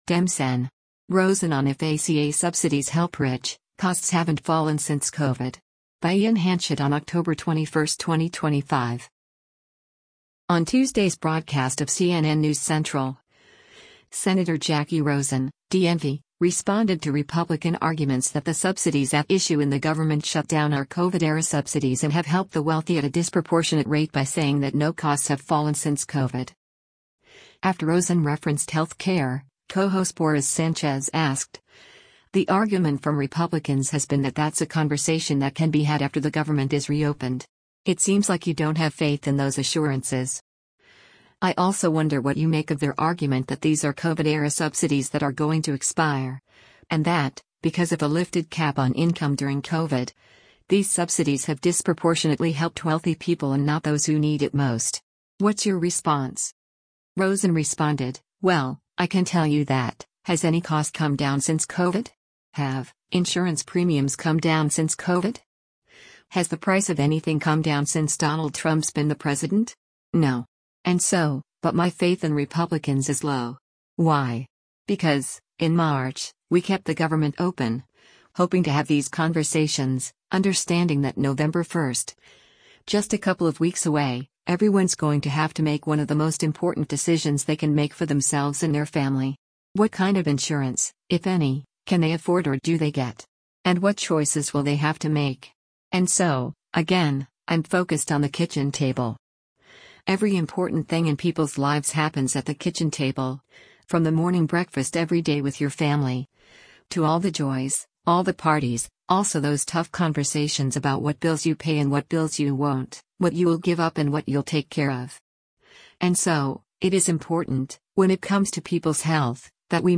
On Tuesday’s broadcast of “CNN News Central,” Sen. Jacky Rosen (D-NV) responded to Republican arguments that the subsidies at issue in the government shutdown are COVID-era subsidies and have helped the wealthy at a disproportionate rate by saying that no costs have fallen since COVID.